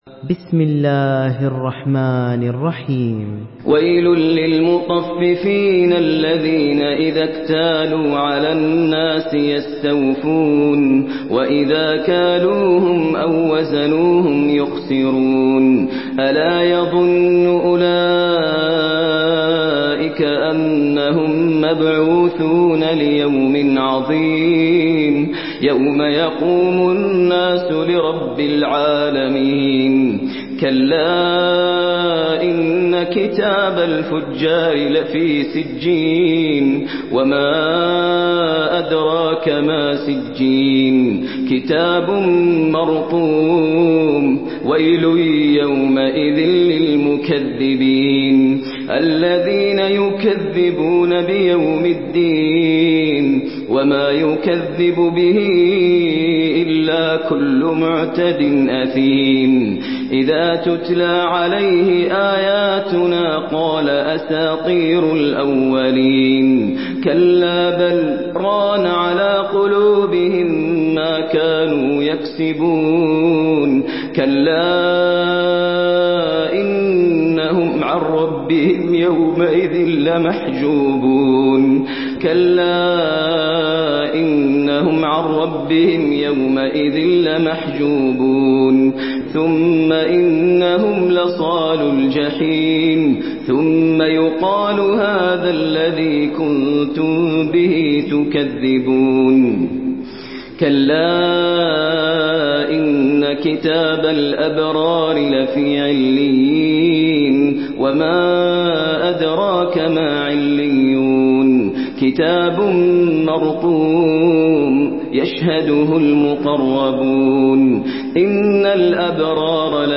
Surah المطففين MP3 by ماهر المعيقلي in حفص عن عاصم narration.
مرتل حفص عن عاصم